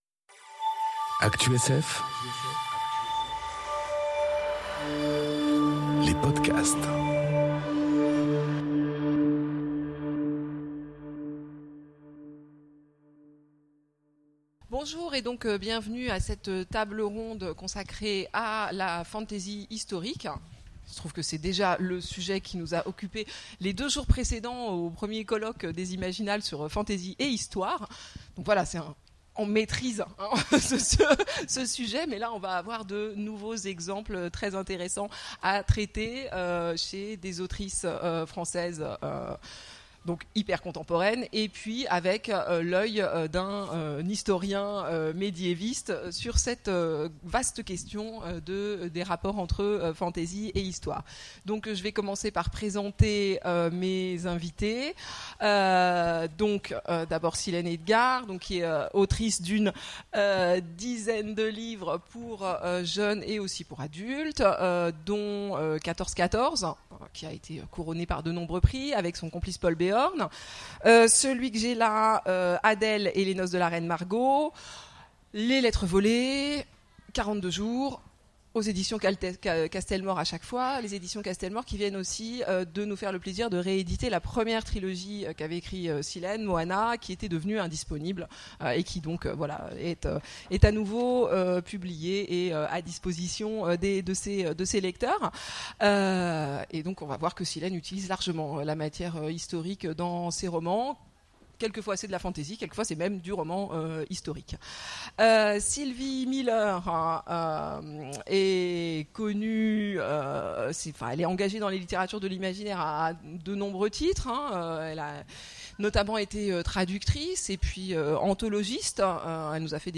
Conférence La fantasy historique... Une histoire qui n'a jamais existé ? enregistrée aux Imaginales 2018